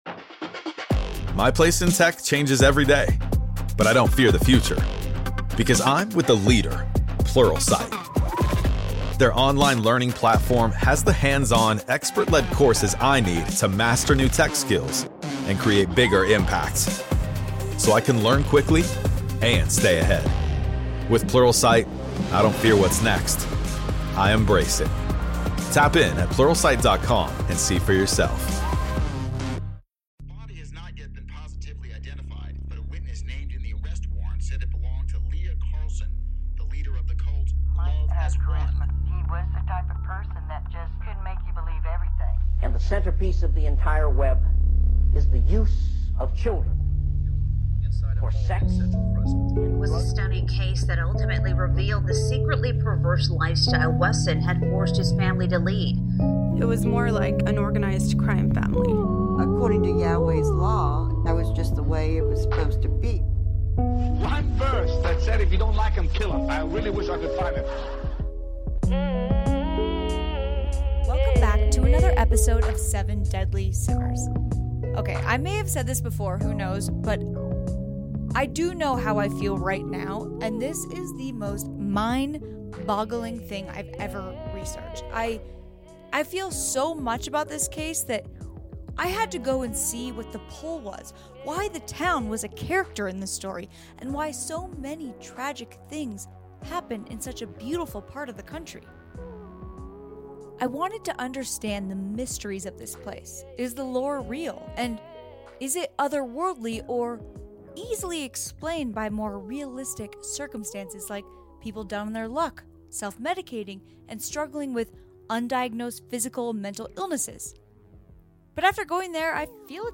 In the UFO shop that loud noise you hear is a little kid playing a game too FYI.